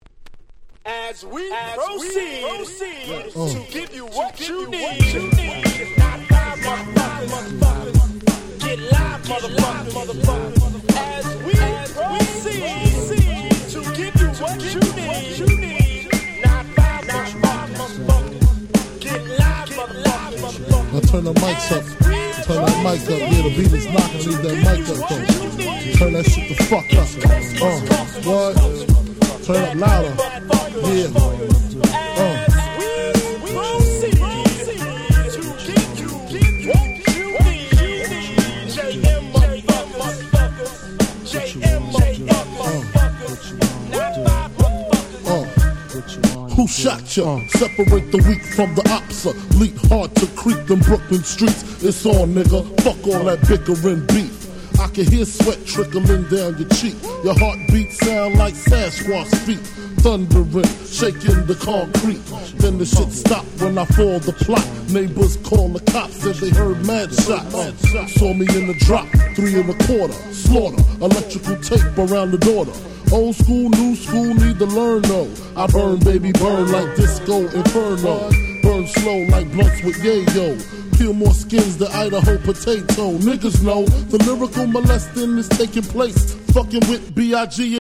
90's Hip Hop Super Classics !!